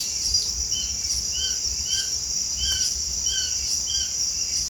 Astur bicolor guttifer
Nombre en español: Esparvero Variado
Nombre en inglés: Bicolored Hawk
Localidad o área protegida: Parque Nacional El Palmar
Condición: Silvestre
Certeza: Fotografiada, Vocalización Grabada
Esparvero-variado-1_1.mp3